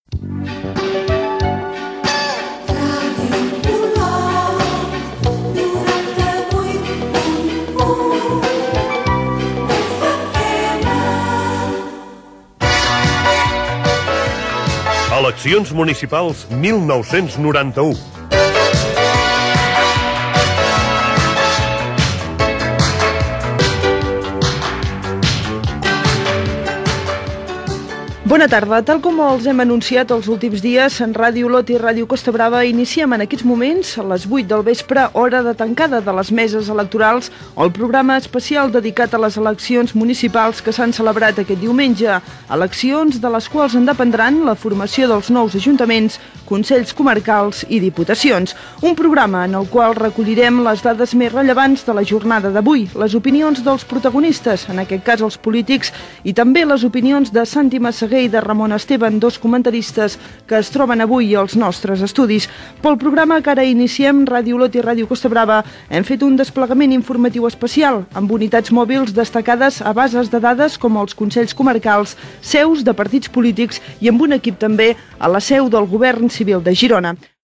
Indicatiu de l'emissora, careta del programa, presentació
Informatiu
Emissió conjunta feta amb Ràdio Olot.